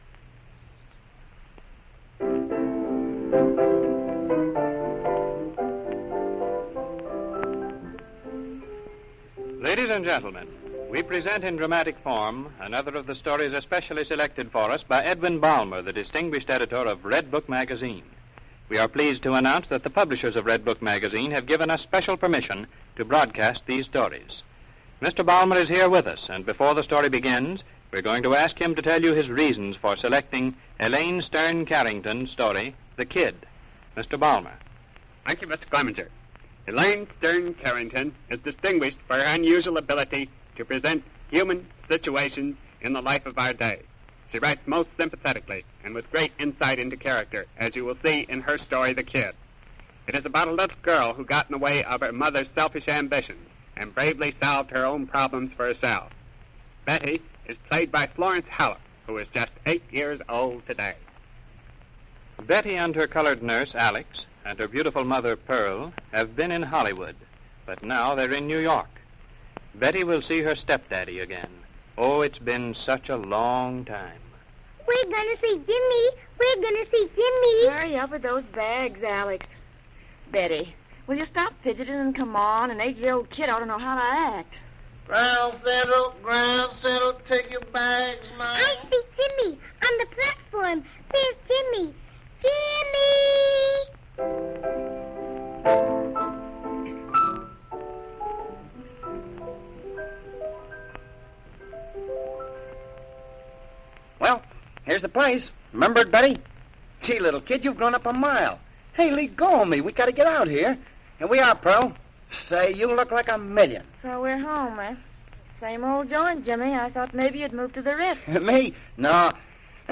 One such gem from the past is the episode titled 'The Kid' from the Redbook Dramas series, which aired on June 23, 1932. This episode, like many others in the series, was a 15-minute segment based on short stories that were originally published in Redbook Magazine.